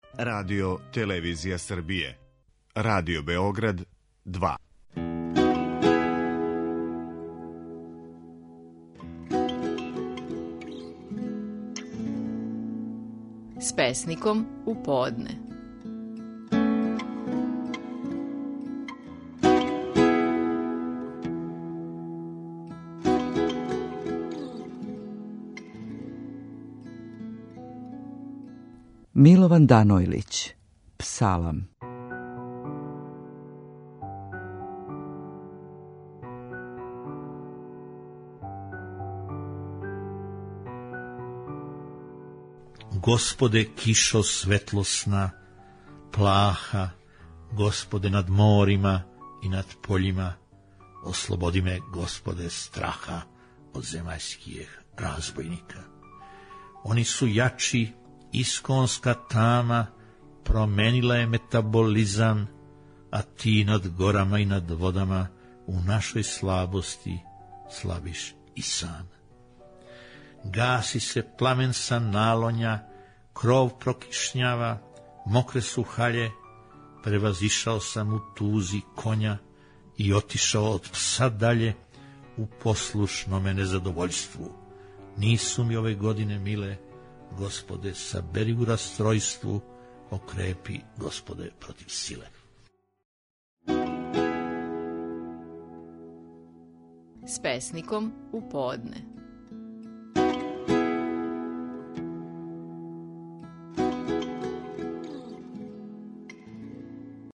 Стихови наших најпознатијих песника, у интерпретацији аутора.
Милован Данојлић говори своју песму „Псалам".